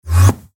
PLAY Minecraft Enderman Teleport Sound
enderman-teleport.mp3